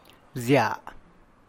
gyiarziny[gyìa’rzihny]